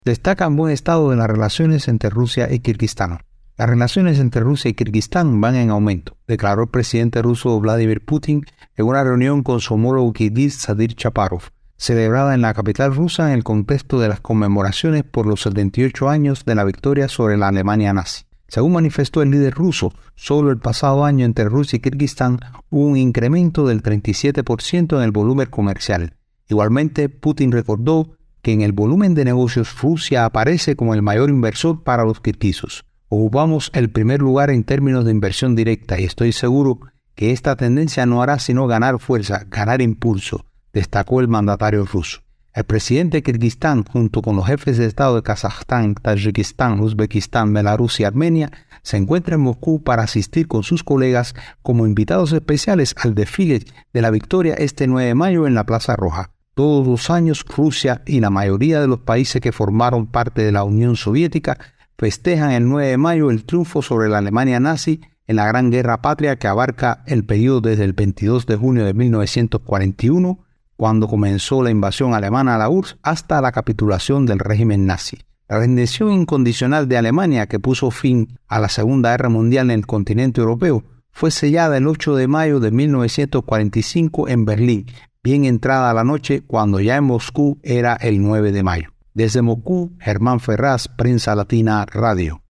desde Moscú